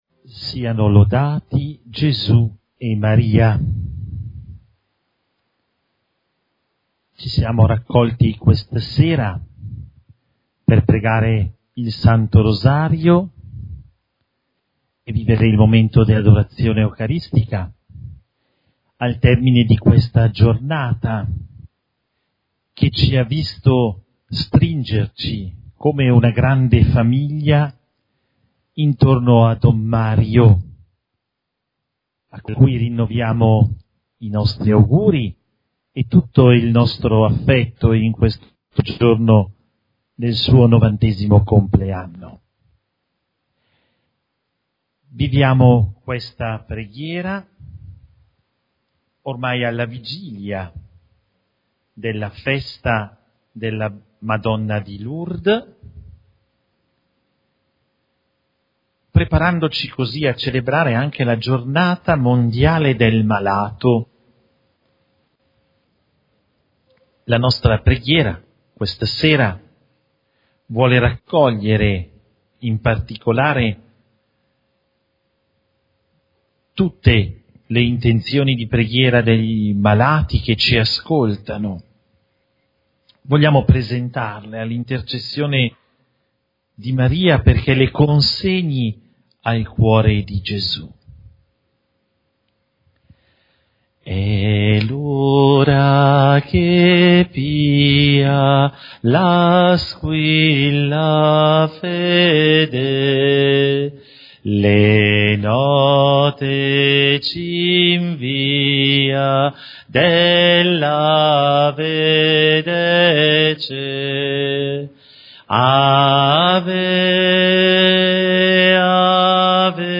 Dalla Cappellina di Maria: serata mariana eucaristica